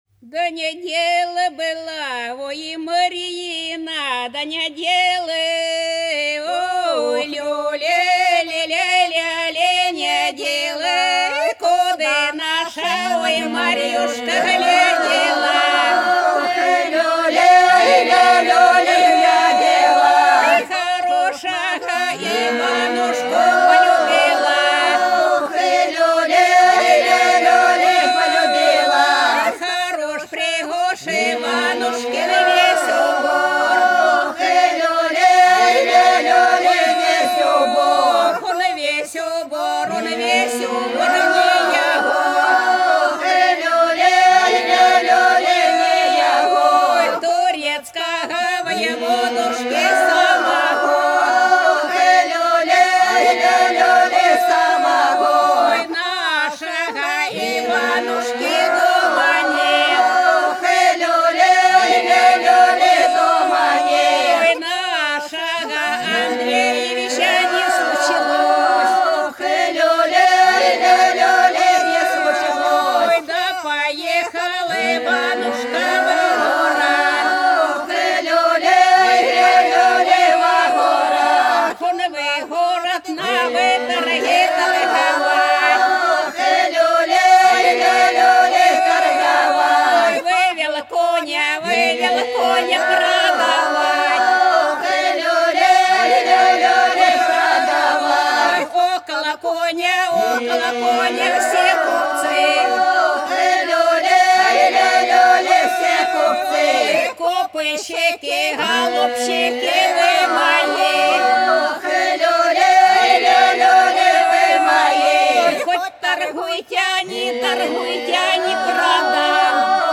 По-над садом, садом дорожка лежала Да не дело было, Марьюшка, не дело - свадебная (с.Фощеватово, Белгородская область)